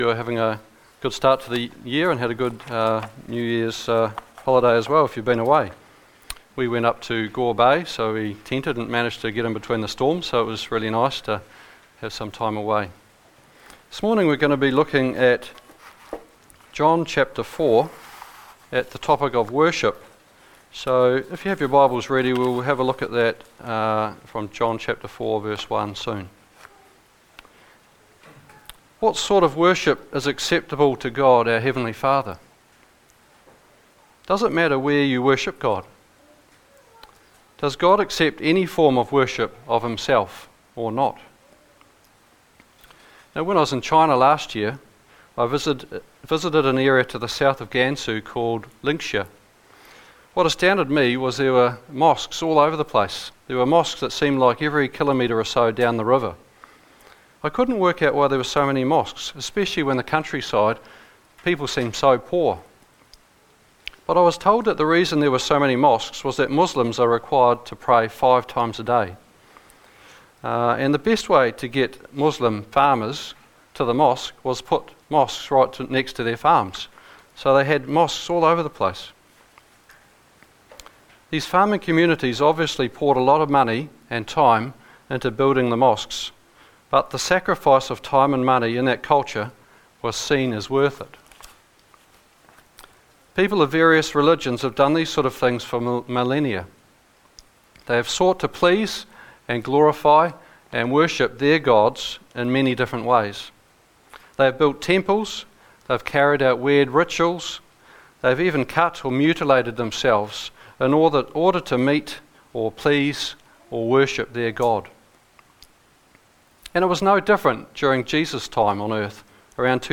From Series: “Standalone Sermons“